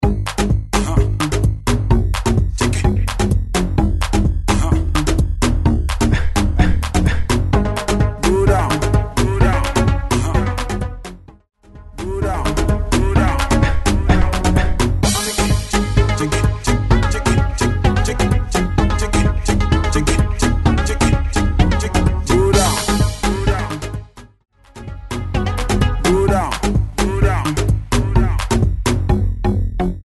128 BPM
Gqom